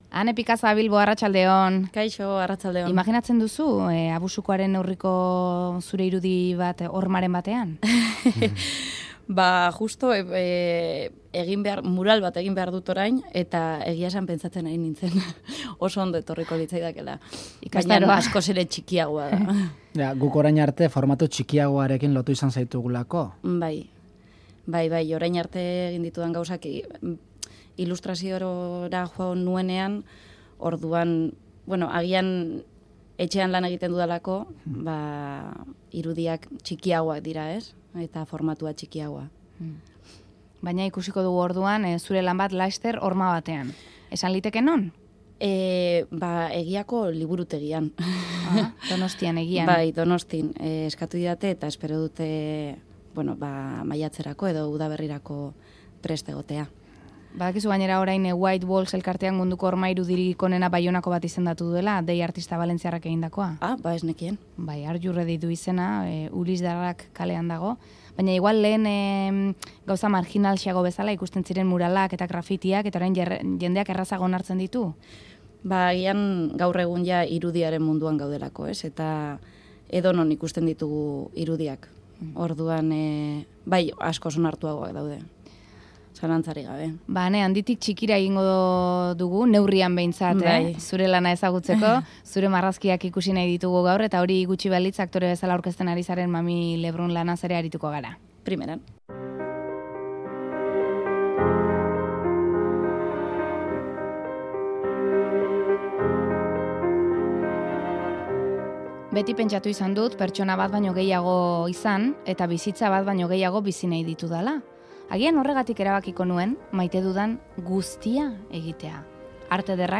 Hiru elkarrizketa, arratsalde bakarrean.